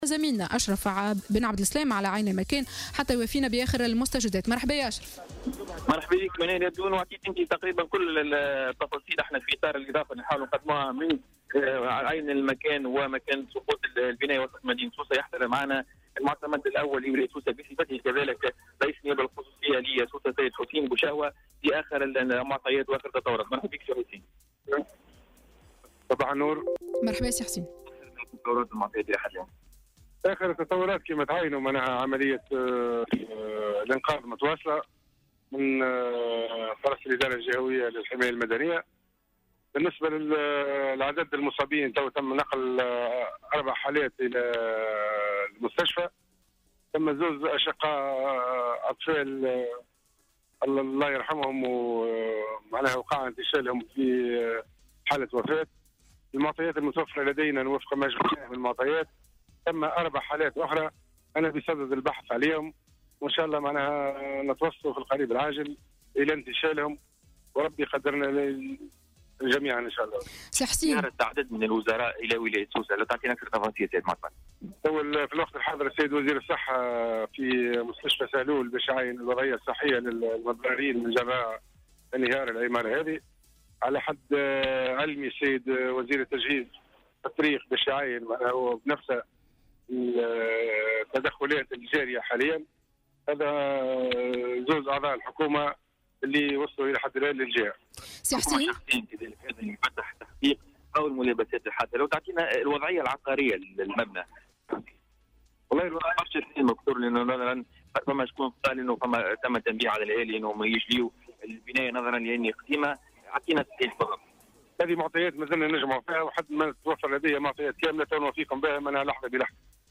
أكد رئيس النيابة الخصوصية لبلدية سوسة، حسين بوشهوة لـ"الجوهرة أف أم" تواصل عمليات البحث عن 4 مفقودين اثر انهيار بناية وسط مدينة سوسة ليلة البارحة.